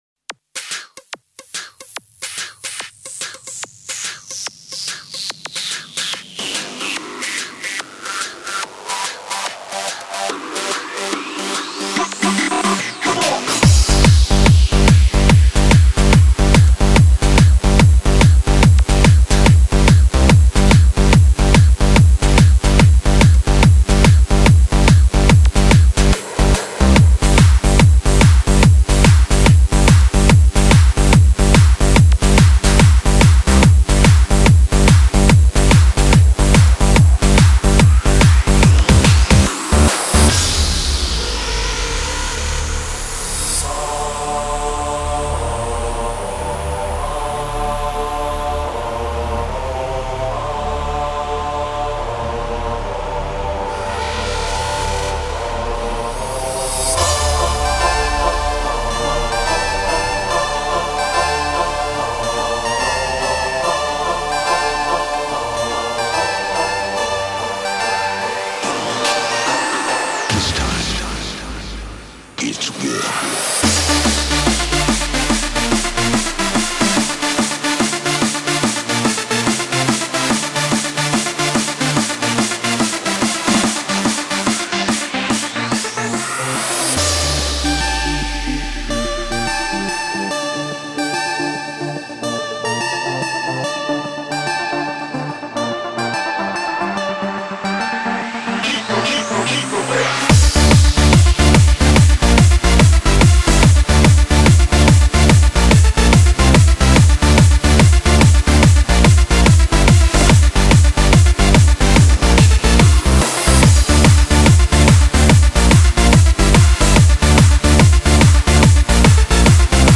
Назад в ¤Techno Dance¤
Жанр:Techno